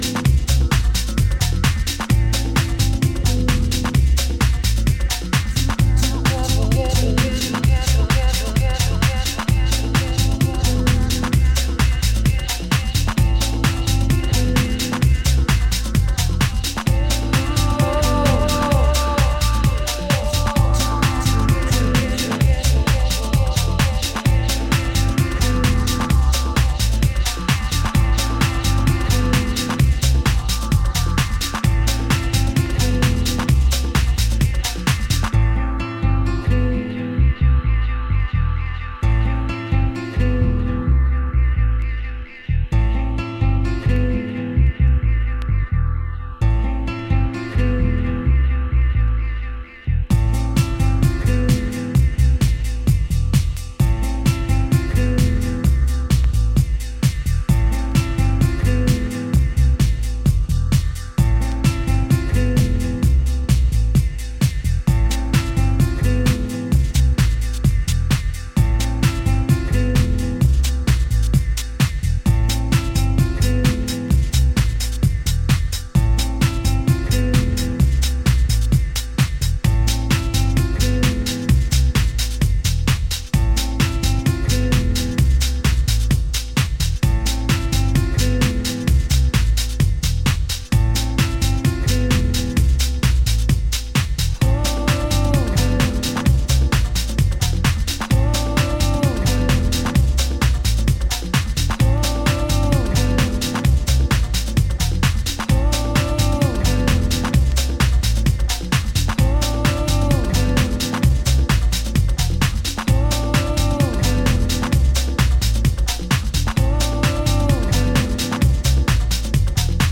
Compilation with fierce house tracks
House Techno